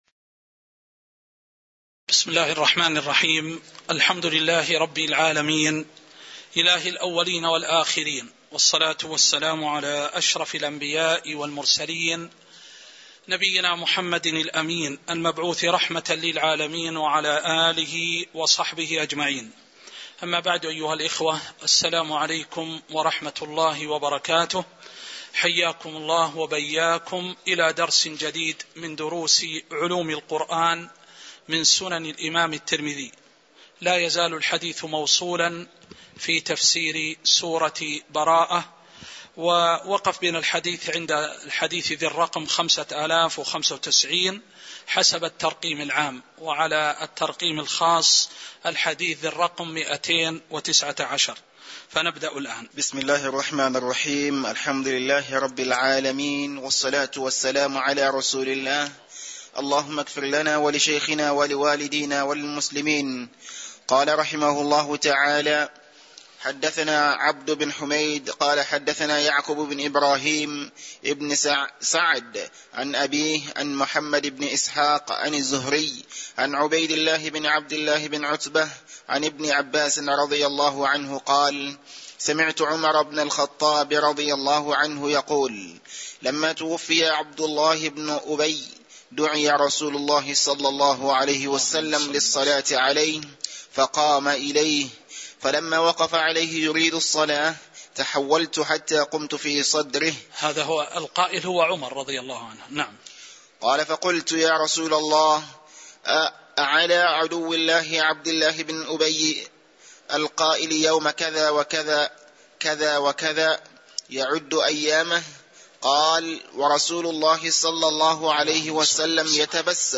تاريخ النشر ٢٩ ربيع الثاني ١٤٤٣ هـ المكان: المسجد النبوي الشيخ